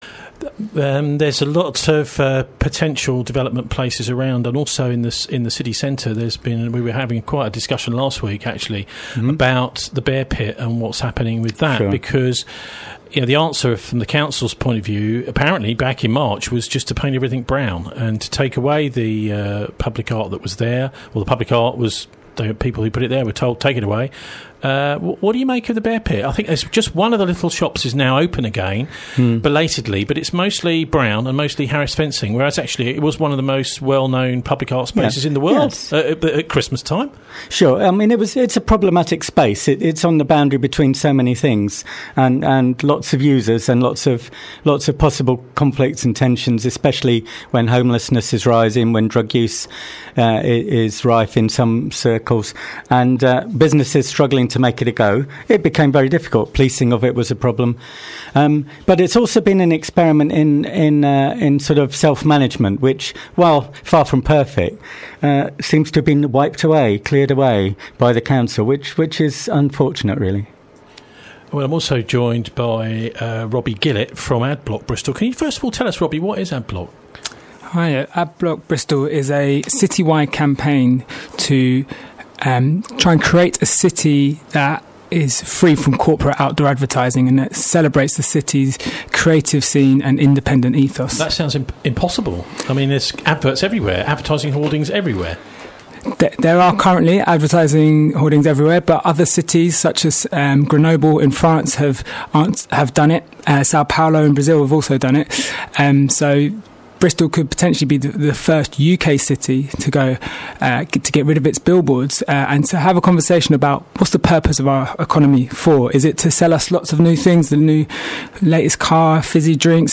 Listen to an excerpt of the BCFM interview below, or find the complete session here.